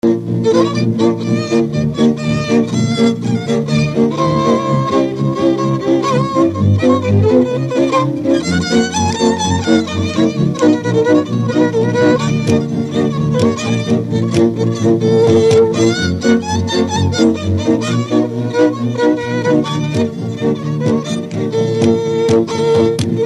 Dallampélda: Hangszeres felvétel
Erdély - Szolnok-Doboka vm. - Szék
Műfaj: Csárdás
Stílus: 4. Sirató stílusú dallamok